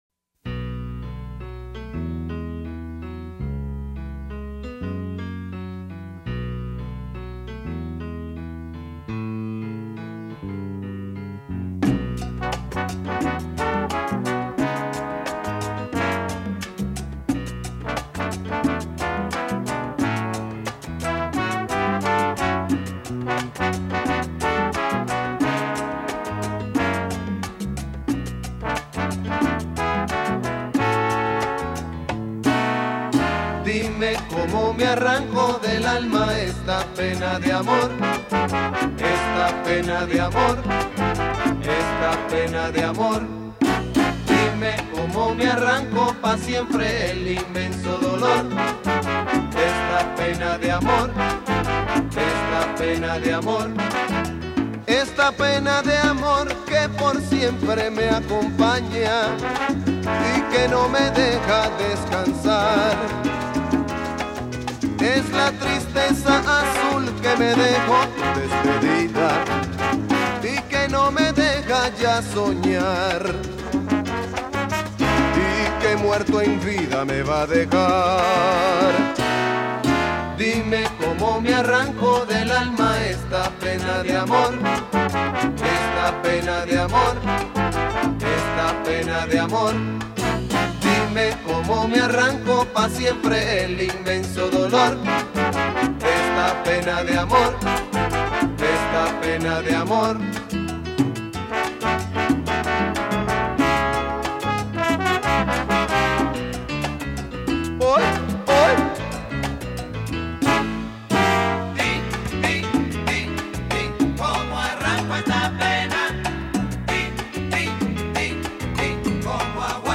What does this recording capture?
Genre: Latin